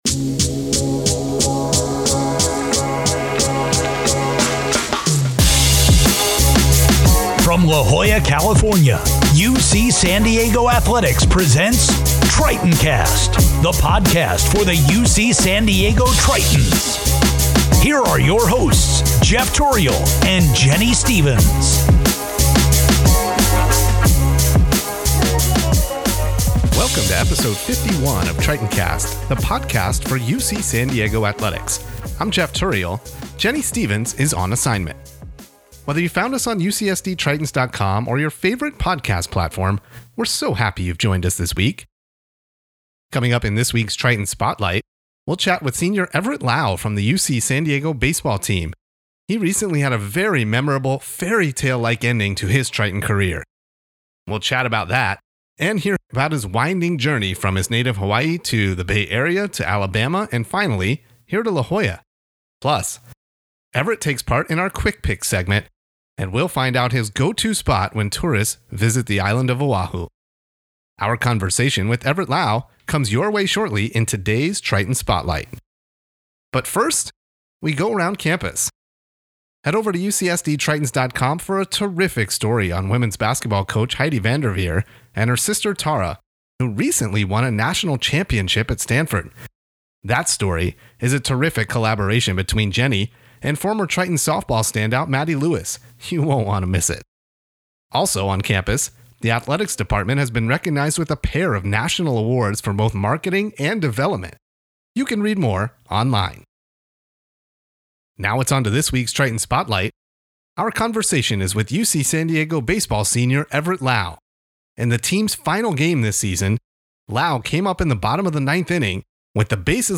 We're talkin' baseball on episode 51 of Tritoncast! In this week's Triton Spotlight, our conversation is with senior